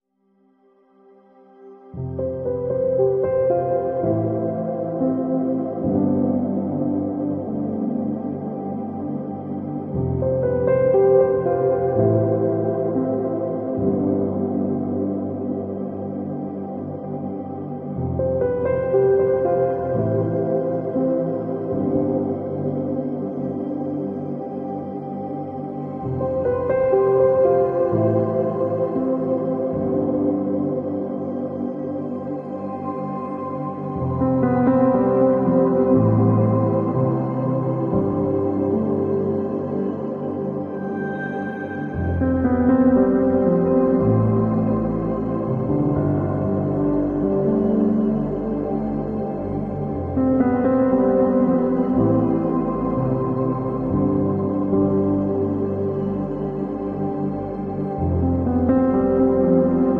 ASMR Satisfying Eating Wax Honey